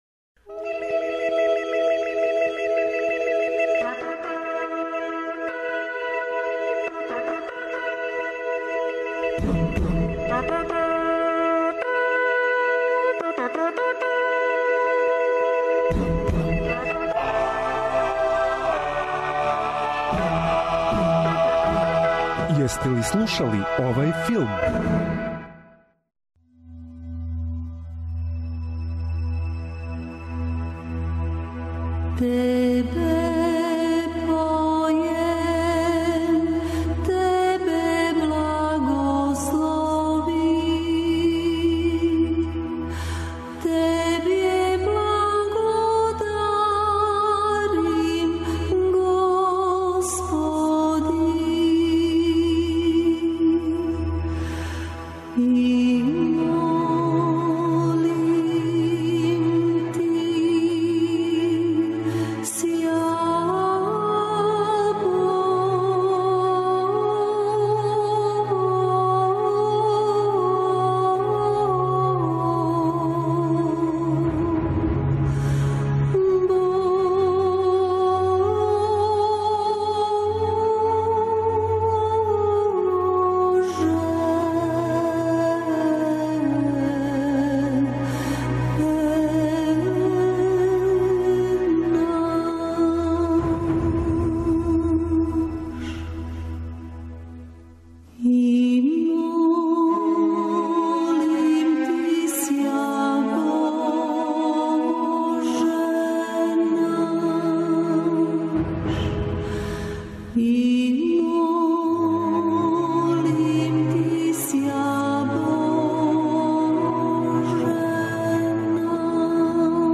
Филмска музика и филмске вести. Заједно ћемо се присетити мелодија које ће нам вратити у сећање сцене из филмова, али и открити шта нам то ново спремају синеасти и композитори.